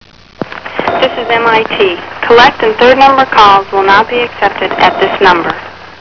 voice of Dormline.
The limitations of step-by-step also required that every incoming caller hear a message with those famous words, "This is MIT, collect and third party calls will not be accepted at this number."